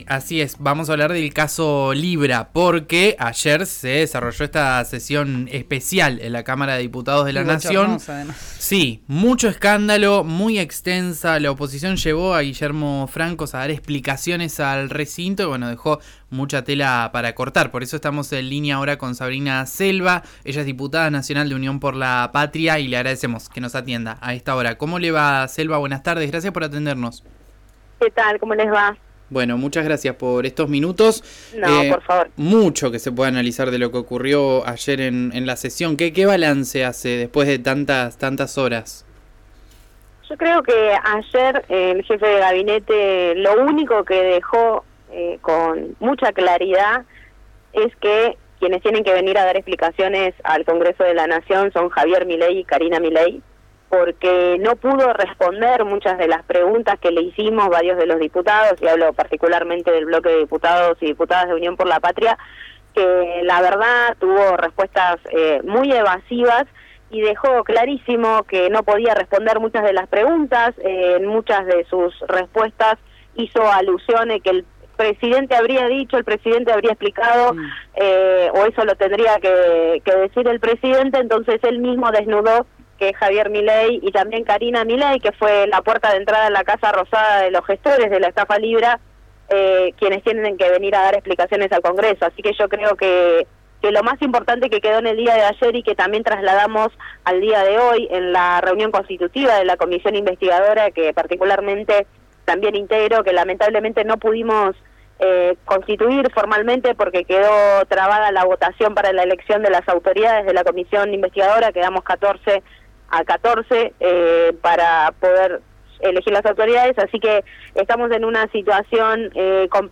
Escuchá a Sabrina Selva, abogada y diputada nacional de Unión por la Patria, en RÍO NEGRO RADIO: